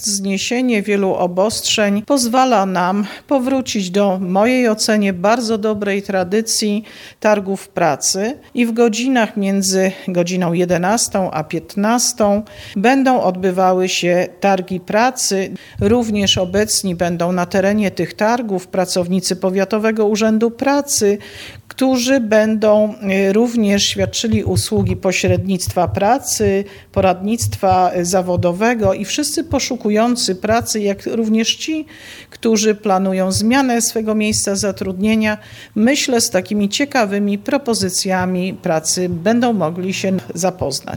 – Mówi Iwona Wiśniewska, Starosta Stargardzki.